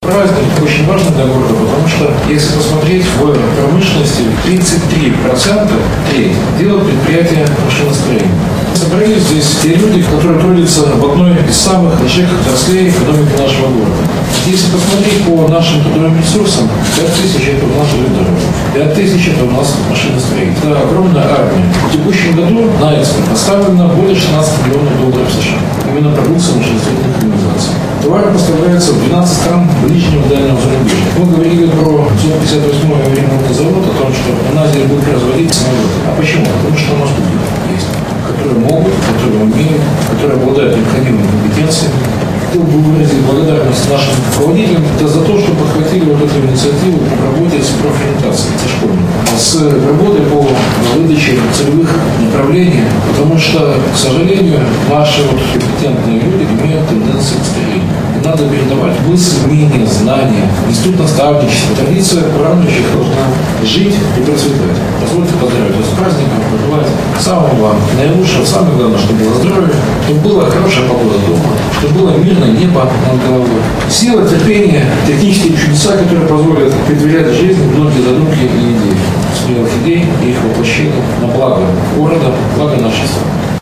Накануне праздника в Барановичском горисполкоме чествовали лучших представителей отрасли нашего города. Поздравил и вручил заслуженные награды председатель горисполкома Михаил Баценко. Люди, собравшиеся в этот день, являются главным двигателем сферы машиностроения и трудятся не только на благо Барановичей, но и Республики Беларусь, — отметил Михаил Баценко.
mashinostroenie-baczenko-sinhron.ogg